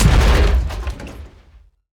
car-crash-3.ogg